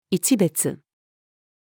一瞥-female.mp3